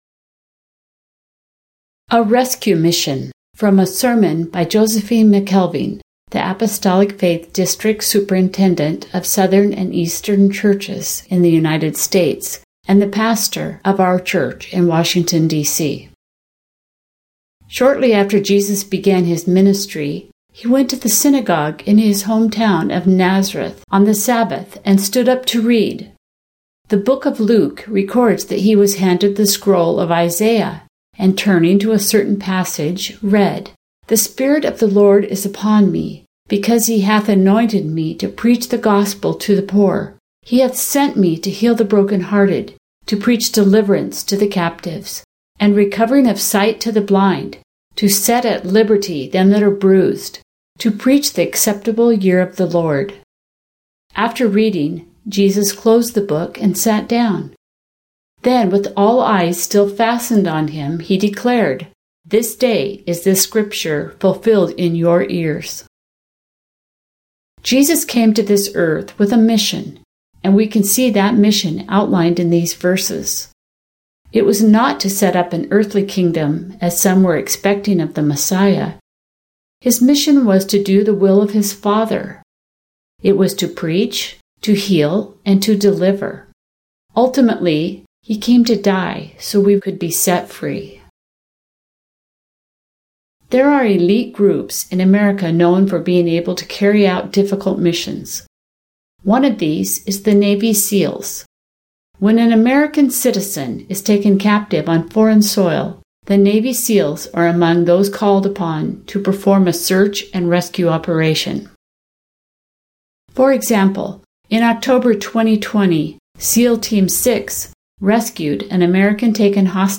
From the Word | This sermon preached on the American Independence Day considers why Christ came to earth and what it means for us today.